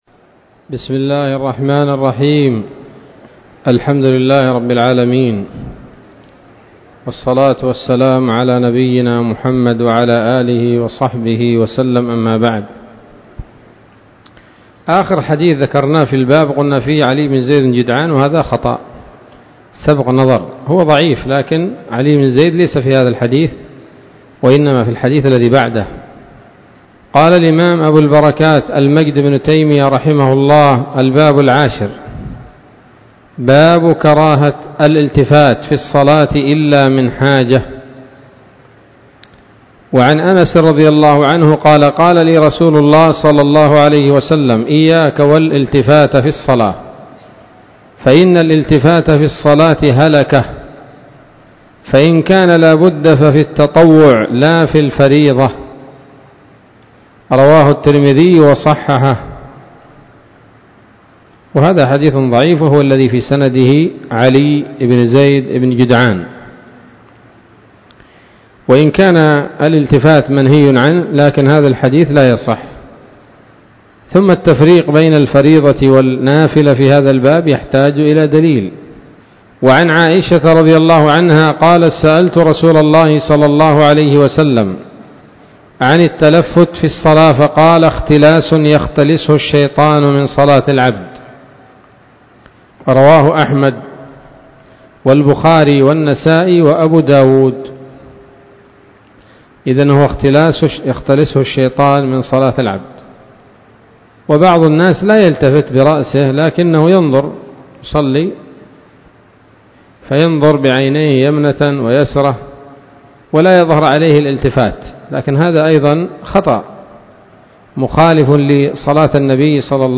الدرس الثاني عشر من أبواب ما يبطل الصلاة وما يكره ويباح فيها من نيل الأوطار